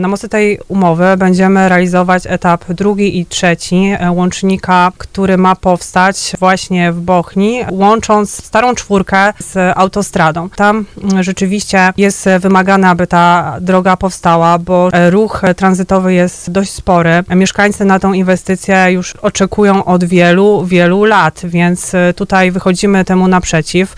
Jak mówiła na antenie Radia RDN Małopolska Marta Malec-Lech z Zarządu Województwa Małopolskiego, budowa łącznika pozwoli na przeniesienie ruchu tranzytowego, co ma zwiększyć bezpieczeństwo mieszkańców.